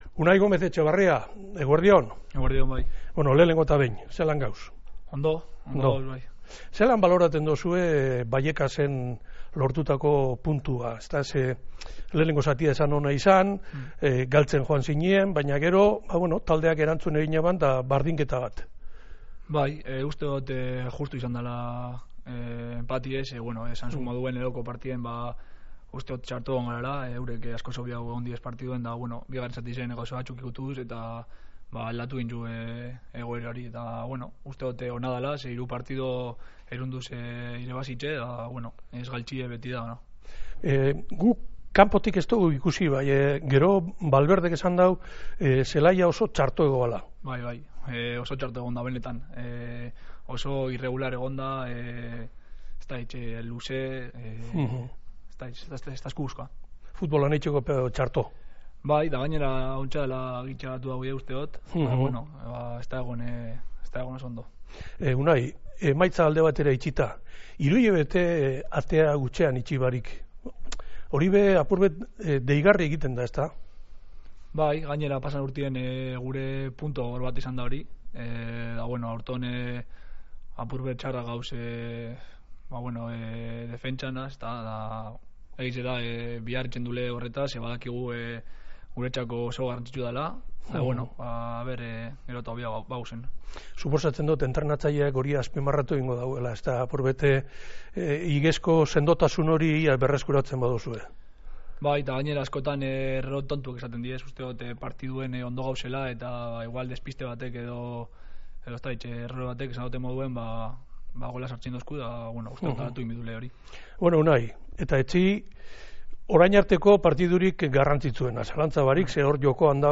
UNAI-GOMEZ-ELKARRIZKETA.mp3